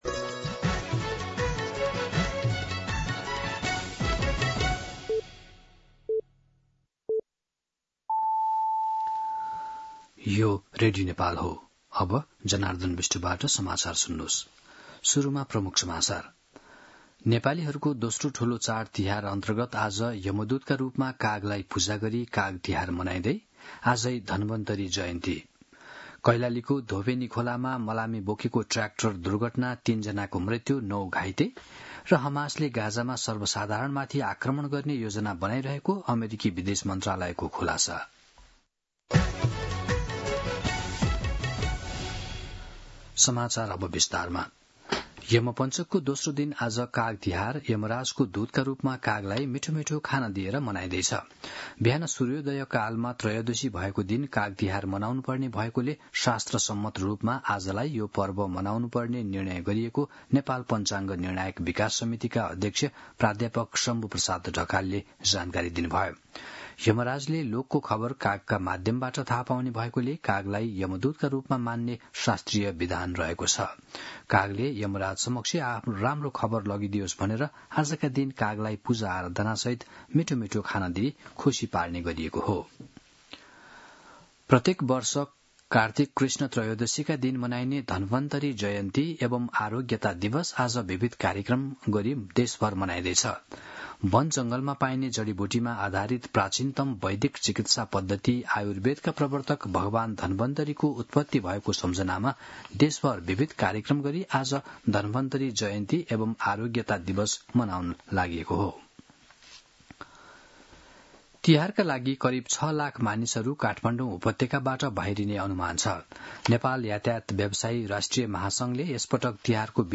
दिउँसो ३ बजेको नेपाली समाचार : २ कार्तिक , २०८२
3-pm-Nepali-News-10.mp3